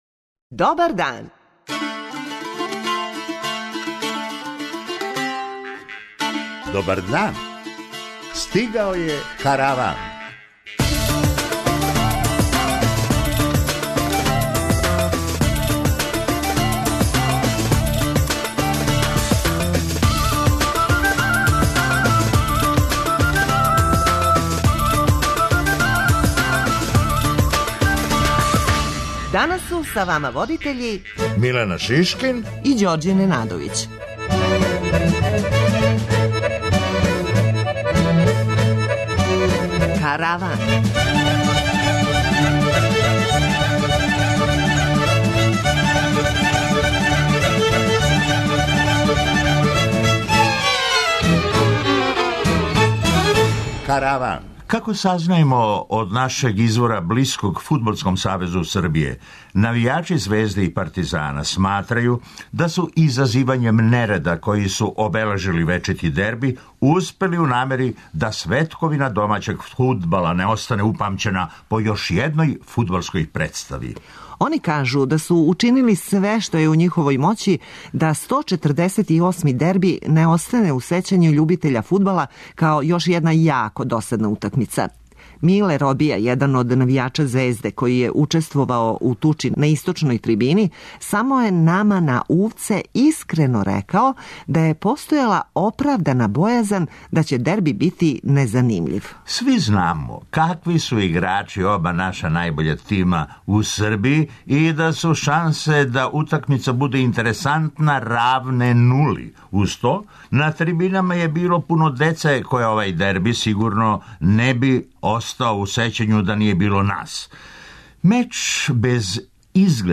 преузми : 23.33 MB Караван Autor: Забавна редакција Радио Бeограда 1 Караван се креће ка својој дестинацији већ више од 50 година, увек добро натоварен актуелним хумором и изворним народним песмама. [ детаљније ] Све епизоде серијала Аудио подкаст Радио Београд 1 Подстицаји у сточарству - шта доносе нове мере Хумористичка емисија Хумористичка емисија Корак ка науци Афера Епстин "не пушта" британског премијера